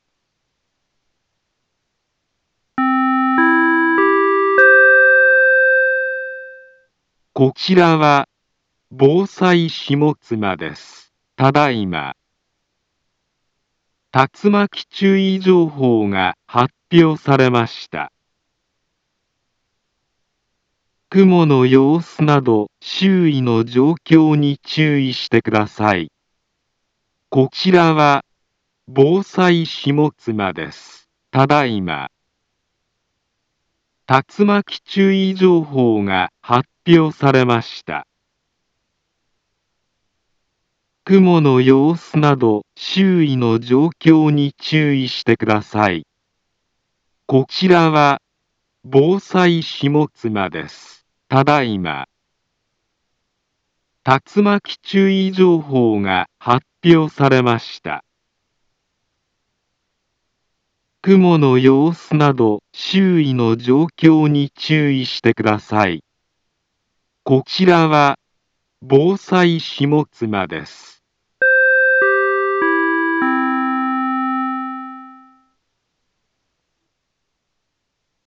Back Home Ｊアラート情報 音声放送 再生 災害情報 カテゴリ：J-ALERT 登録日時：2023-06-28 19:19:49 インフォメーション：茨城県南部は、竜巻などの激しい突風が発生しやすい気象状況になっています。